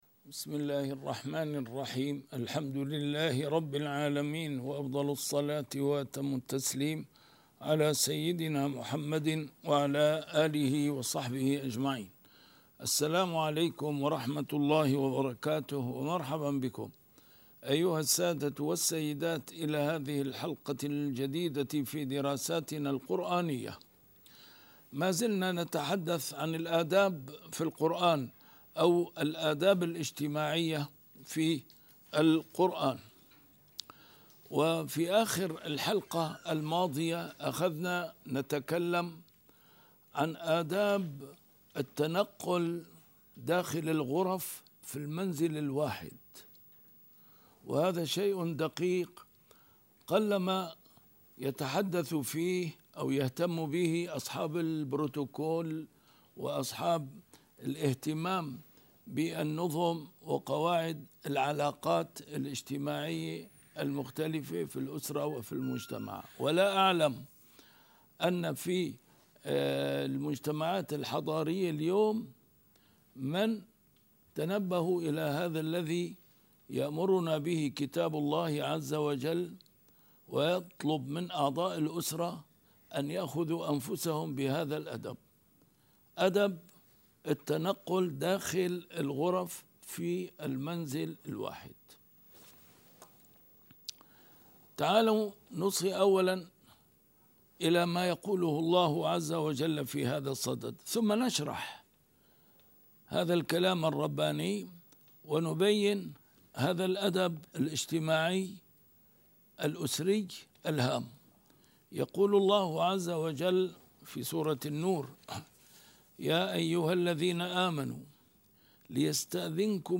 A MARTYR SCHOLAR: IMAM MUHAMMAD SAEED RAMADAN AL-BOUTI - الدروس العلمية - الآداب الاجتماعية في القرآن الكريم - 7- آداب الدخول للبيوت